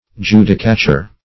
Judicature \Ju"di*ca*ture\ (?; 135), n. [F., fr. LL.